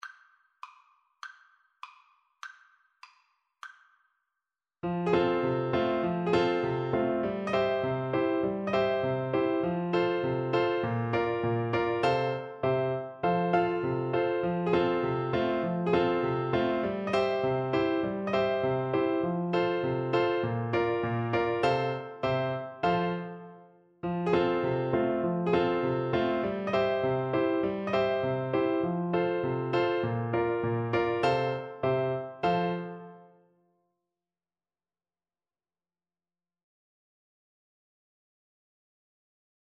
Flute
F major (Sounding Pitch) (View more F major Music for Flute )
Allegro = c.100 (View more music marked Allegro)
A5-Bb6
2/2 (View more 2/2 Music)
Traditional (View more Traditional Flute Music)